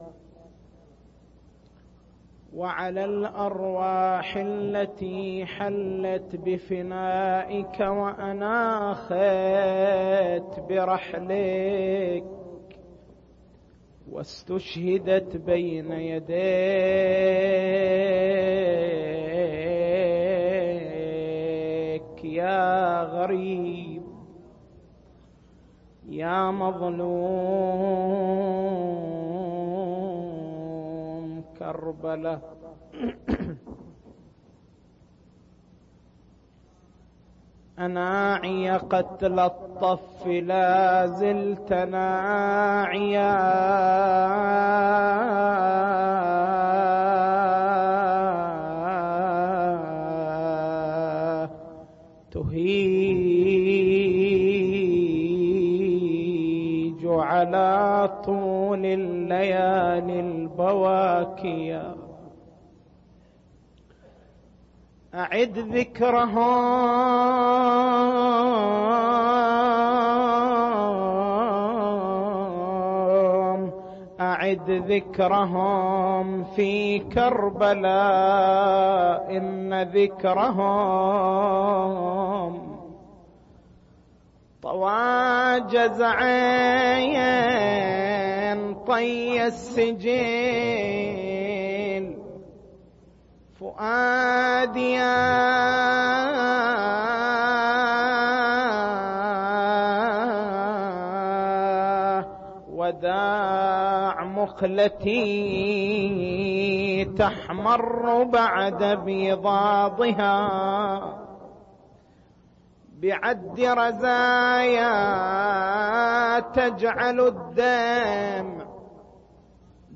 مكتبة المحاضرات